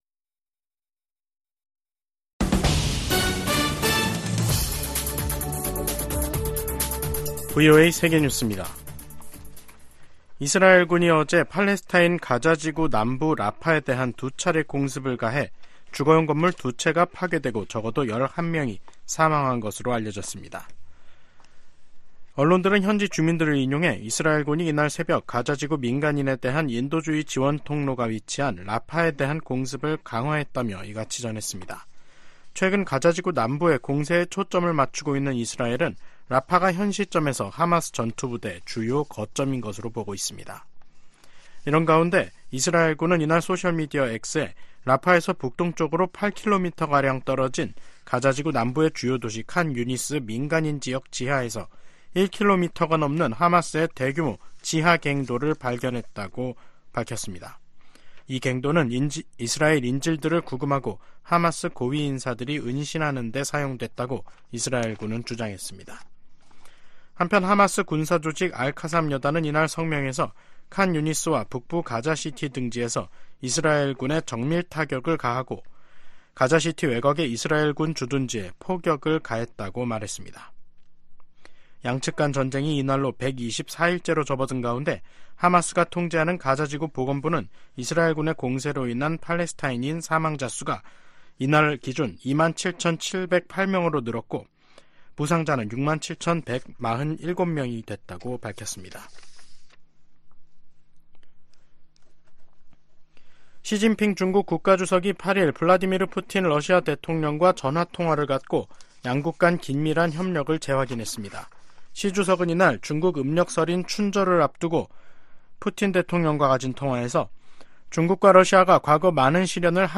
VOA 한국어 간판 뉴스 프로그램 '뉴스 투데이', 2024년 2월 8일 3부 방송입니다. 윤석열 한국 대통령은 북한 정권이 비이성적인 정치세력임으로 이에 대비, 더 튼튼한 안보를 구축해야 한다고 강조했습니다. 미국 정부는 북한-러시아 군사협력이 강화되면서 북한 대량살상무기 개발을 진전시킬 것이라고 우려했습니다. 제이크 설리번 백악관 국가안보보좌관은 북미와 유럽 국가들의 군사 동맹인 나토와 한국·일본 등 아시아태평양 국가들 간 협력의 중요성을 강조했습니다.